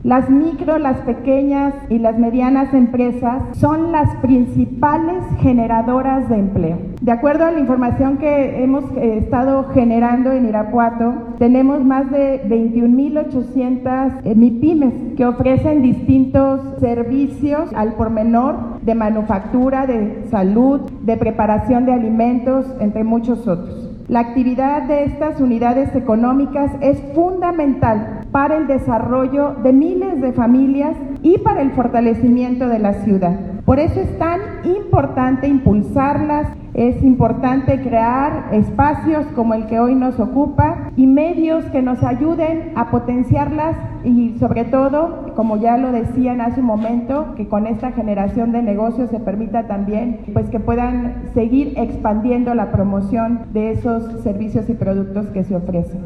Realizan Expo MiPyMe Guanajuato 2022 – Gobierno Municipal de Irapuato
AudioBoletines
Lorena Alfaro García – Presidenta Municipal
Ramón Alfaro Gómez, secretario de Desarrollo Económico Sustentable del Estado